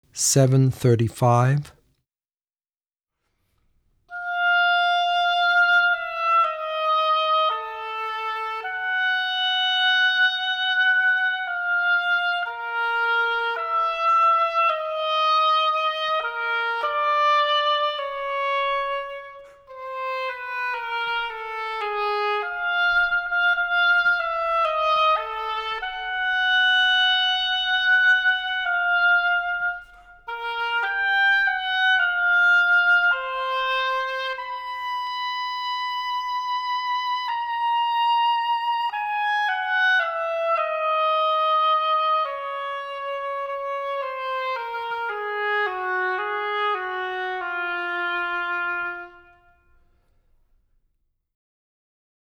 oboe
oboe2 51 Track 51.wav